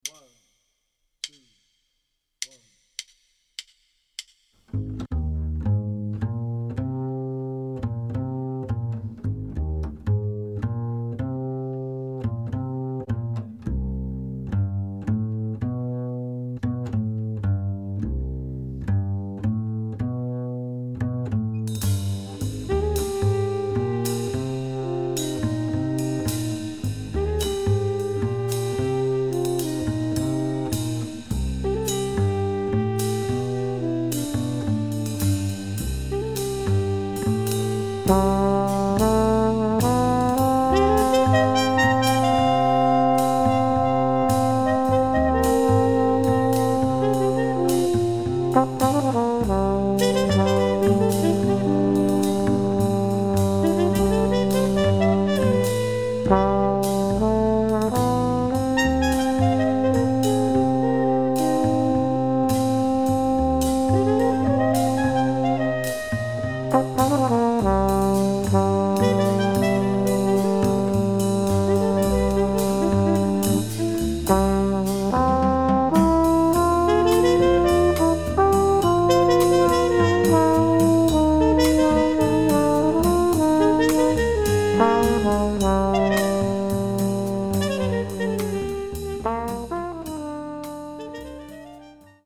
A lyrical Latin song without an obvious key center.
The B section, however, has a bossa groove.